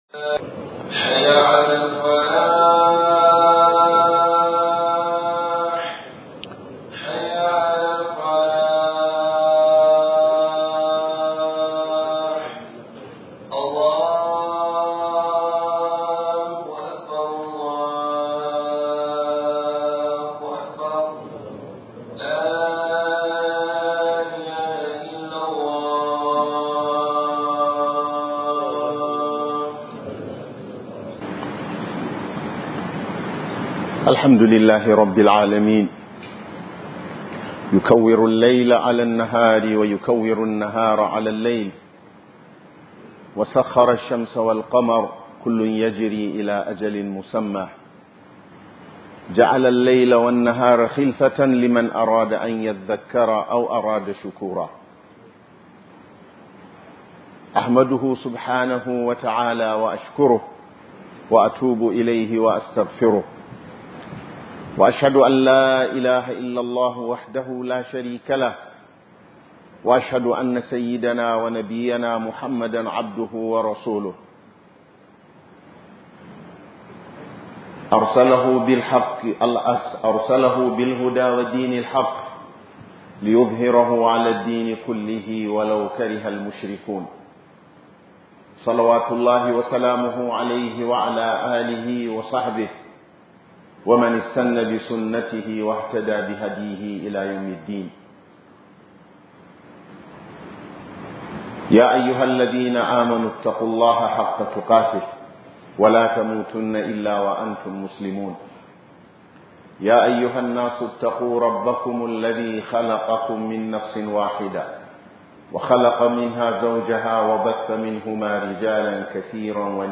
Tsayawa Kyam Akan Ayyukan Alkhairi - HUDUBA